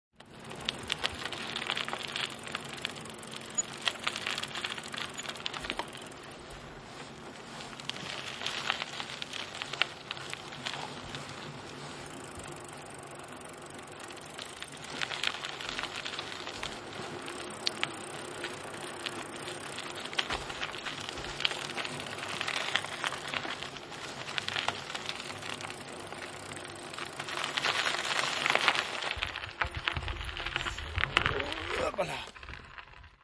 自行车声(48khz/24bit)
描述：踩踏板时自行车链条声音 我喜欢好听的声音。
Tag: 自行车 VELO